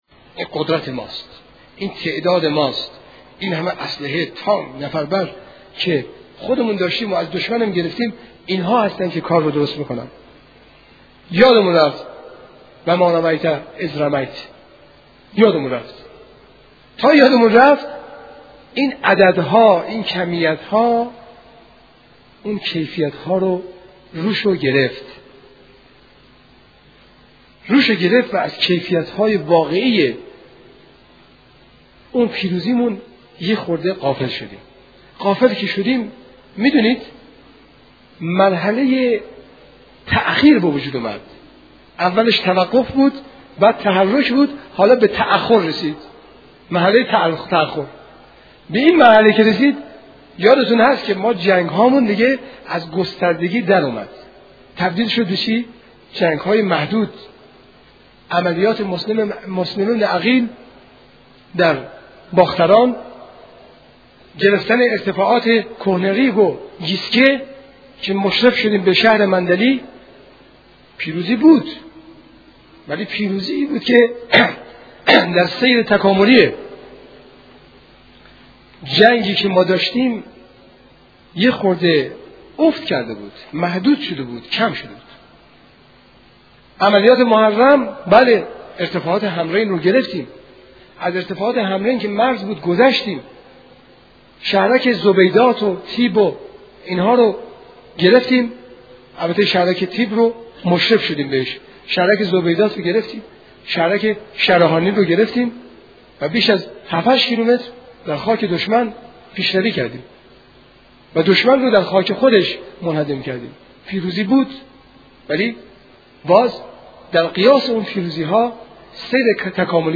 این صوت راجب سخنرانی شهید صیاد شیرازی در شکوفایی عملیات والفجر 2 و والفجر 3 و تحلیل نواقص در عملیات هایی است که نتیجه ی آن پیروزی های افت کرده بود.